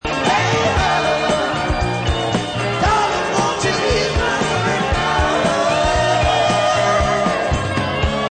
Now we hit the chorus.